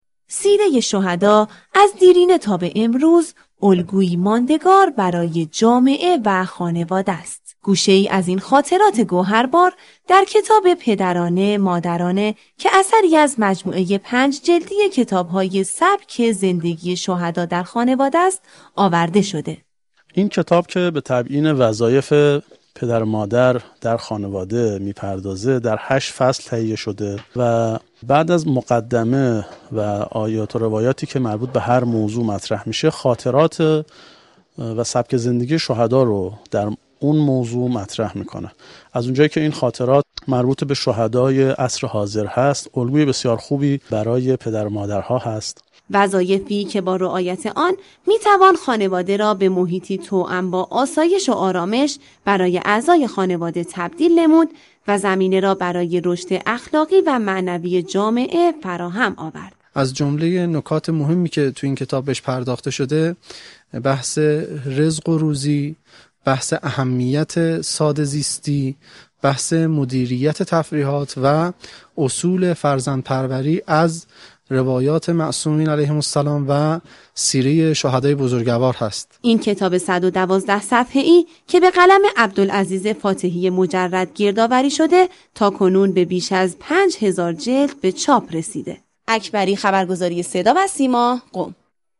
گزارش همكارم